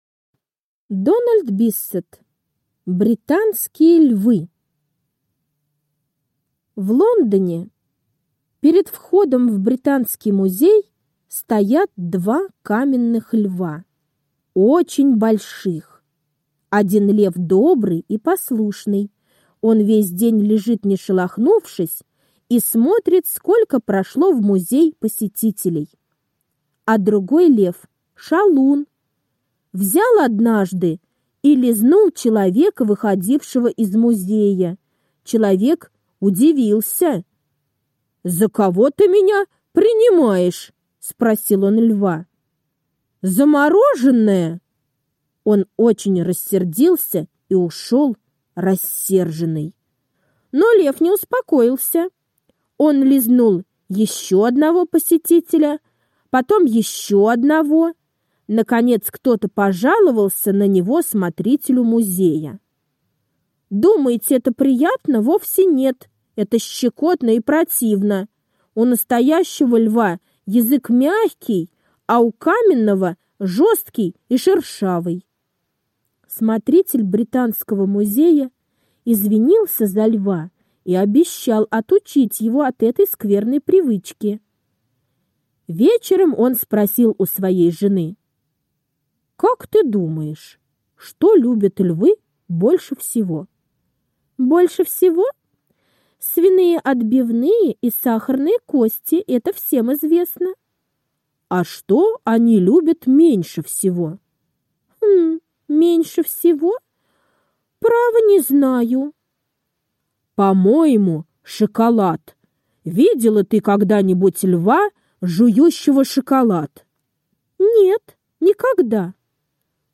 Британские львы — аудиосказка Дональда Биссета. Сказка про каменного льва, который вздумал лизать за щеку посетителей музея…